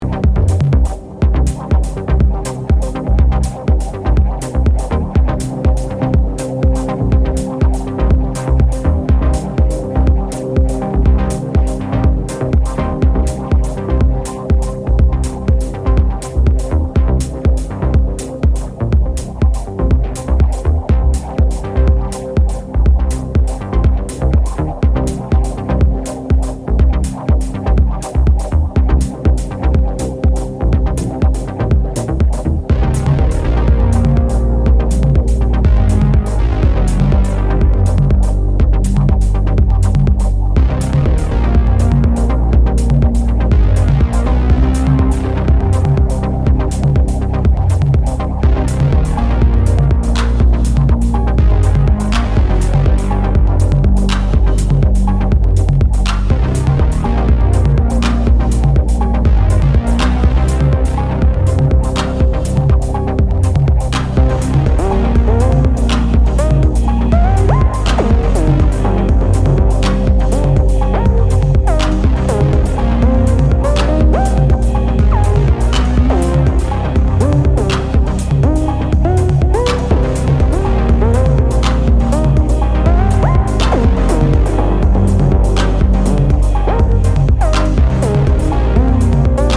deranged deep house